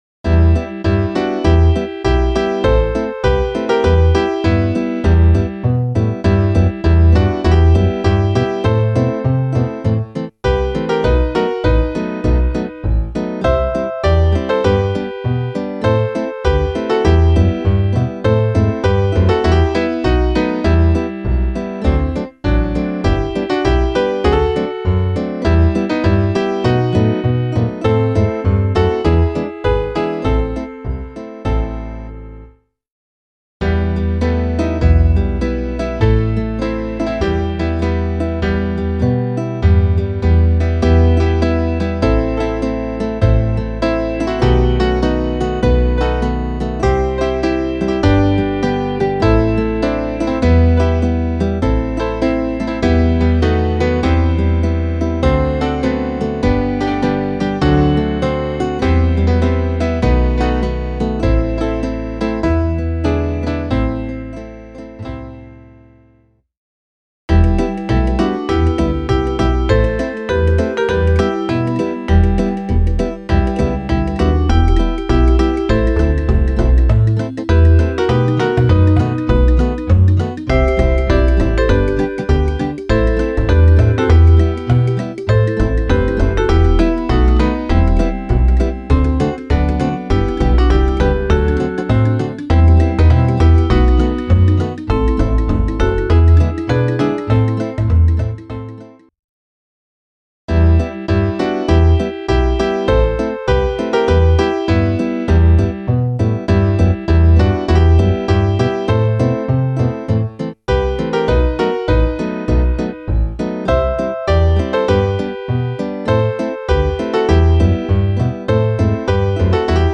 Klaviersatz
Variationen